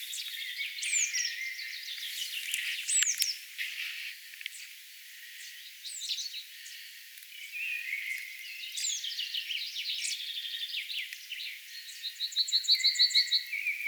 töyhtötiaisemon poikasmaisia ääniä
sen kerätessä männyn latvuksessa poikasilleen
ilm_toyhtotiaisemon_aania_kun_se_keraa_poikasille_ruokaa_latvuksessa.mp3